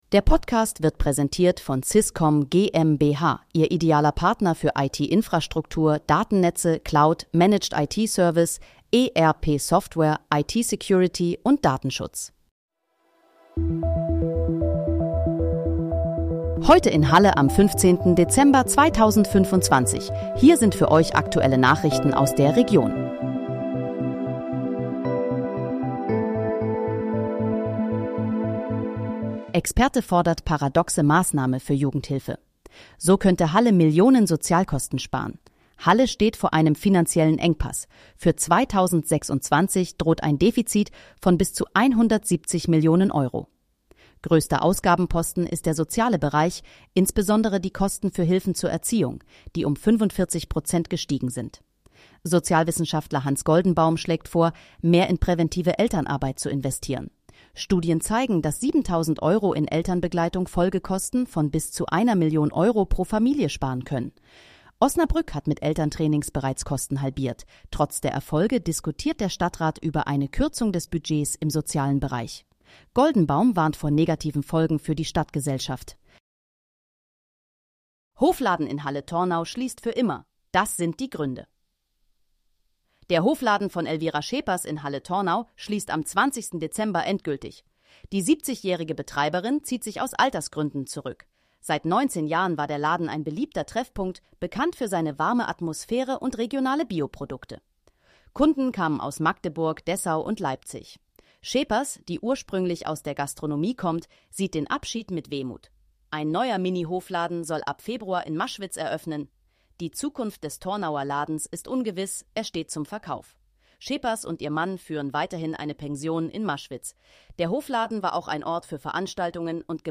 Heute in, Halle: Aktuelle Nachrichten vom 15.12.2025, erstellt mit KI-Unterstützung
Nachrichten